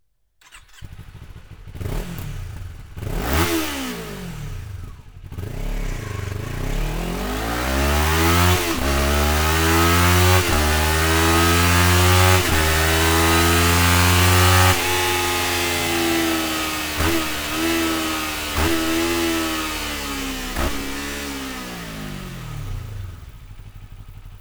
Akrapovic Slip-On Line (Titanium) Endschalldämpfer mit Titan-Hülle und Carbon-Endkappe, mit EU-Zulassung; für
Sound Slip-On Akrapovic